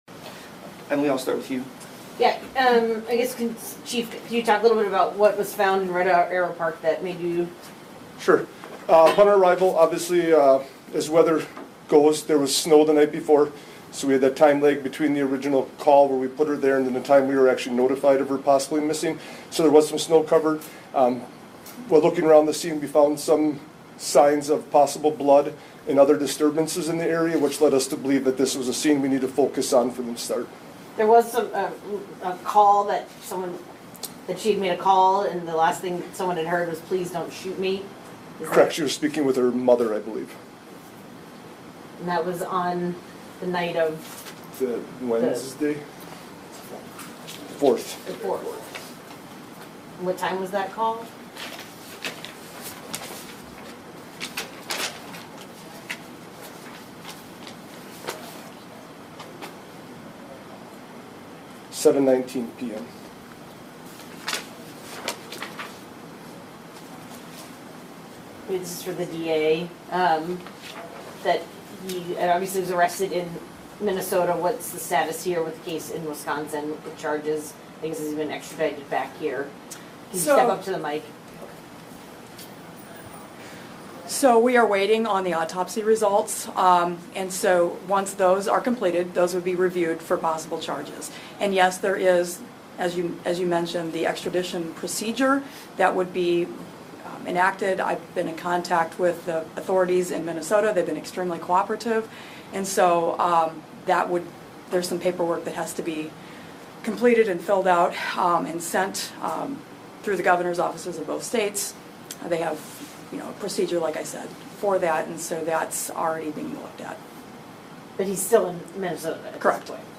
CLICK TO HEAR LAW ENFORCEMENT RESPOND TO MEDIA QUESTIONS
marinette-q-and-a.mp3